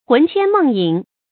魂牽夢縈 注音： ㄏㄨㄣˊ ㄑㄧㄢ ㄇㄥˋ ㄧㄥˊ 讀音讀法： 意思解釋： 牽：牽掛；縈：縈懷。